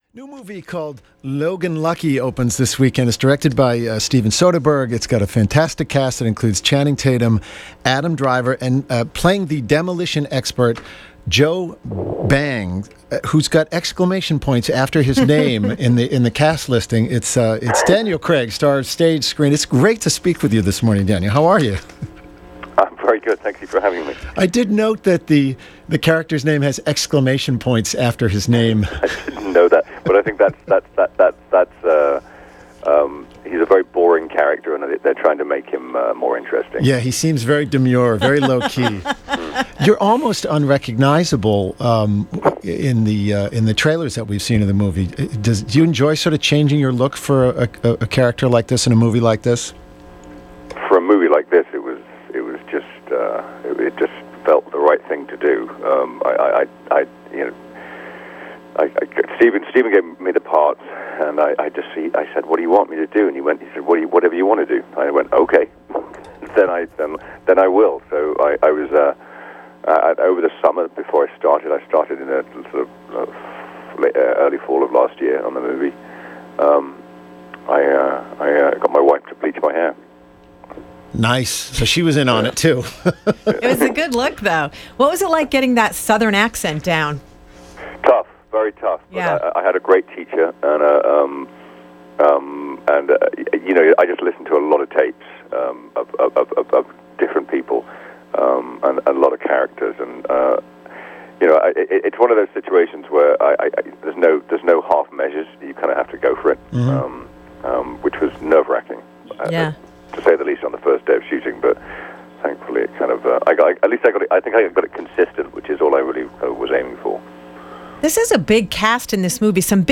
Daniel Craig took to the airwaves n US radio this morning to promote his new film 'Logan Lucky.'
Daniel-Craig-Interview.wav